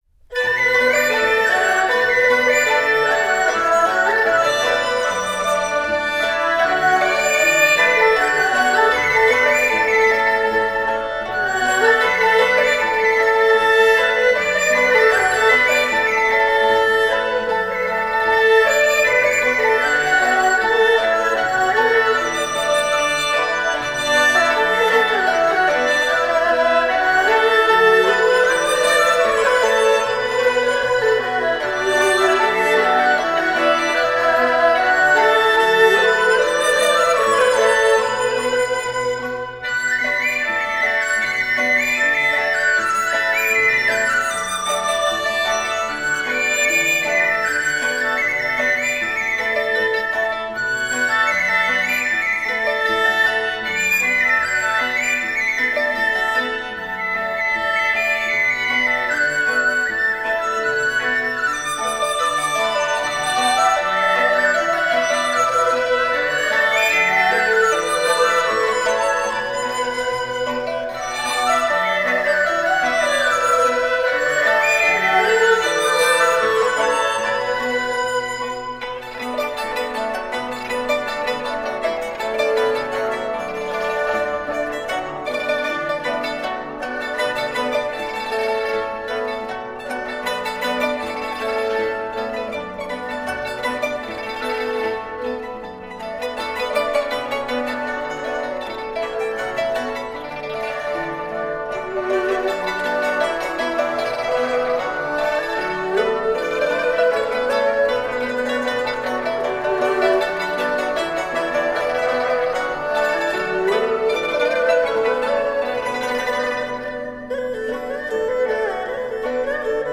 五音疗法
医护人员根据老奶奶情绪低落的证候特点播放了养阳助心的徵调式乐曲，很快就吸引了老奶奶的注意力，听到熟悉的曲调时竟也跟着轻声哼唱打起了拍子。